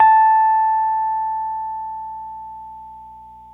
RHODES CL0HR.wav